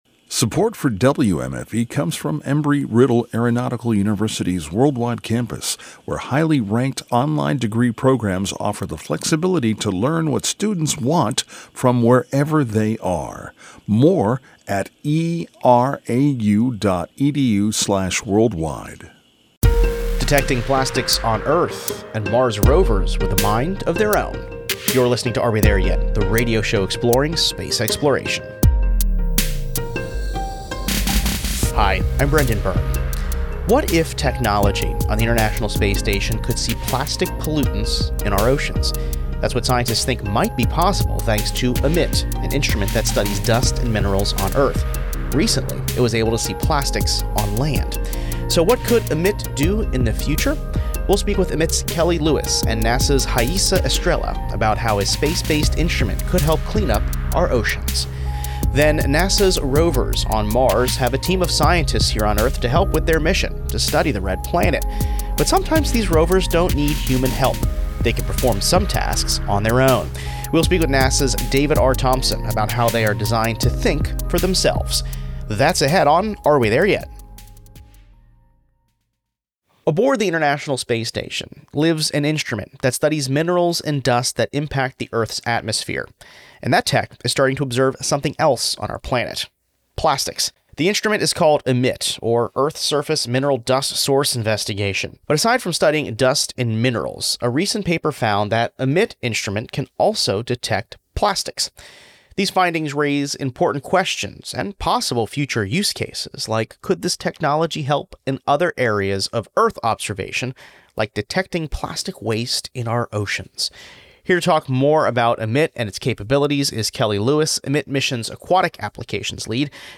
Download - 'Escaping Gravity': A conversation with NASA's former deputy administrator Lori Garver | Podbean